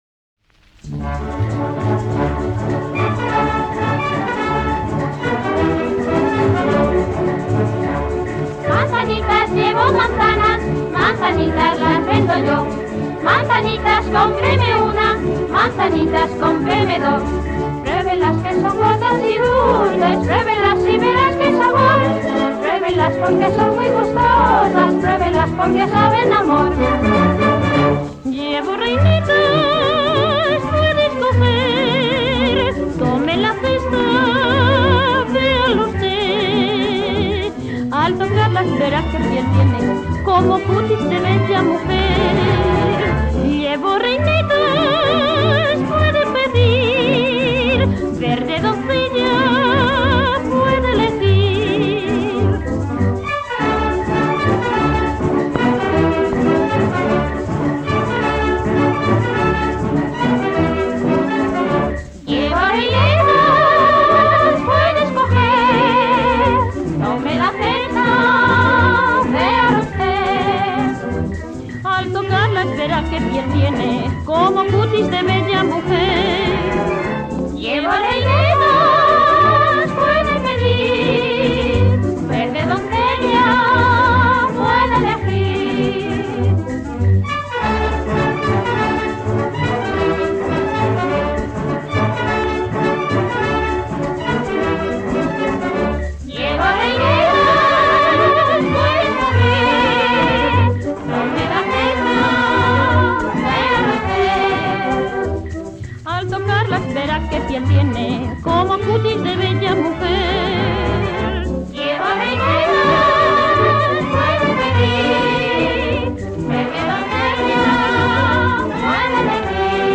danzón
78 rpm